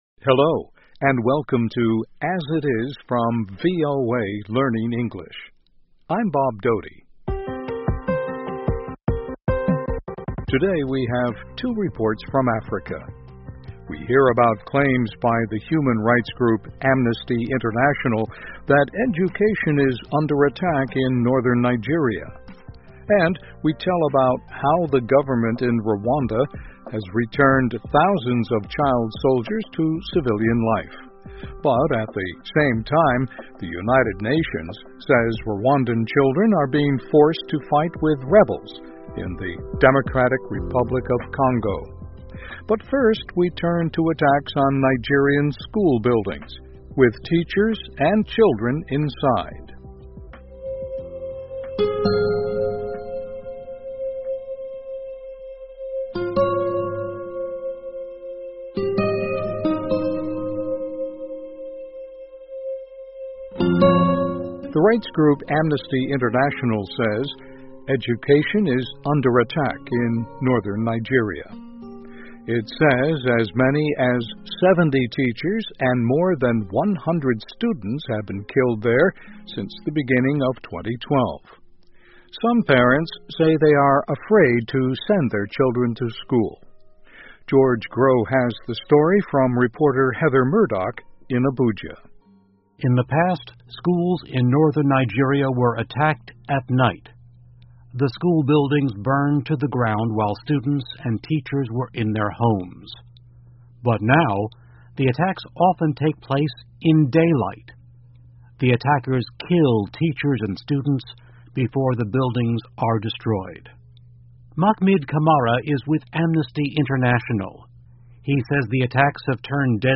VOA慢速英语2013 人权组织谴责武装分子袭击尼日利亚学校 听力文件下载—在线英语听力室